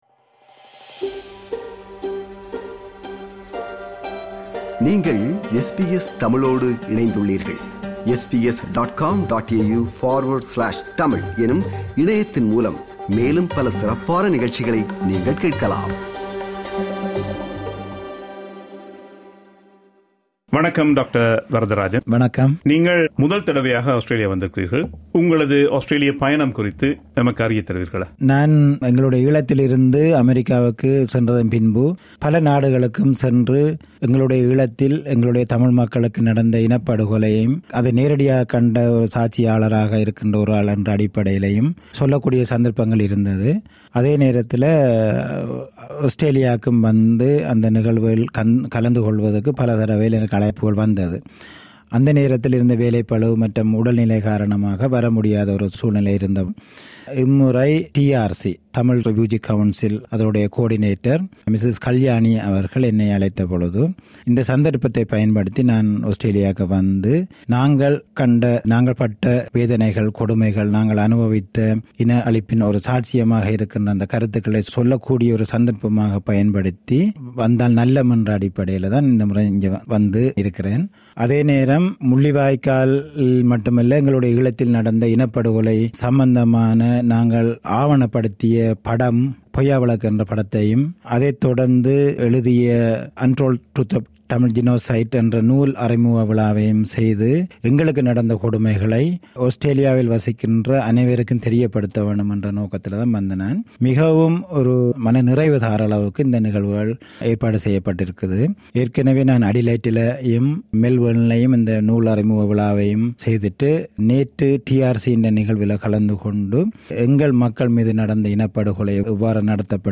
His biography, chronicling his experiences, has been published as a book. During his visit to Sydney for the book launch, we had the opportunity to meet and speak with him at the SBS studio.